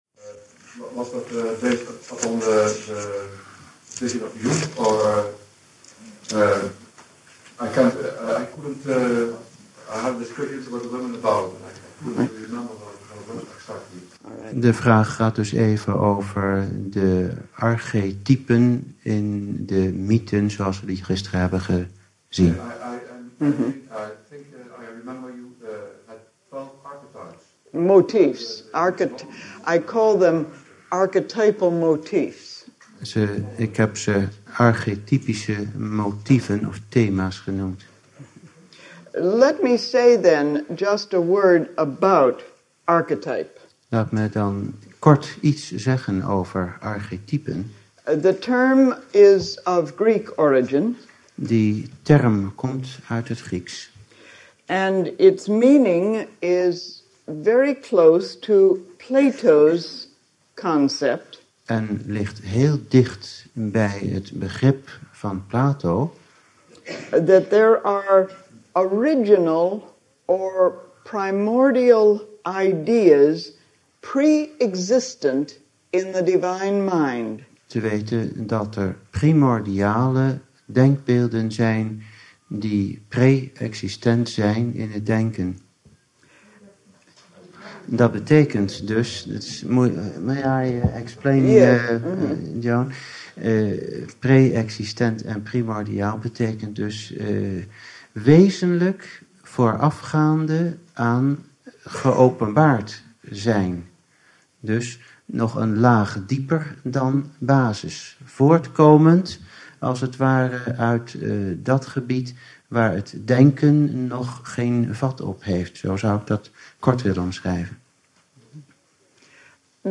Secret Doctrine talks
The talks below were recorded in Naarden, the Netherlands in August 1988.